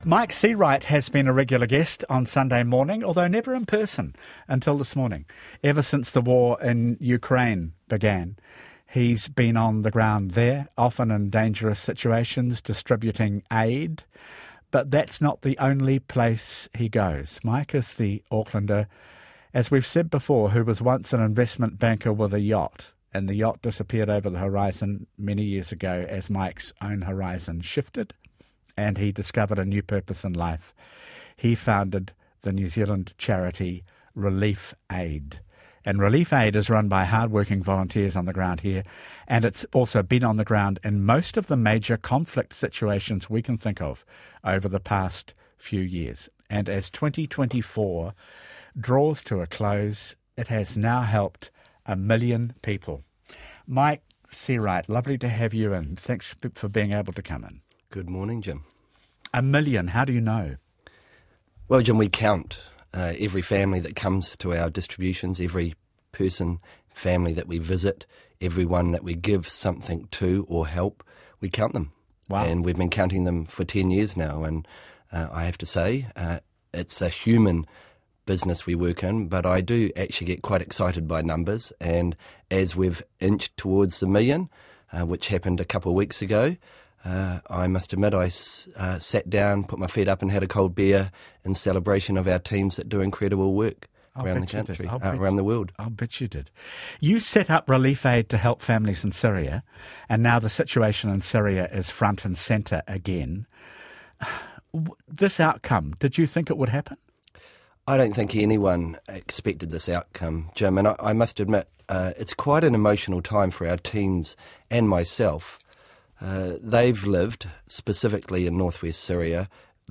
joins Jim Mora on RNZ to celebrate a huge milestone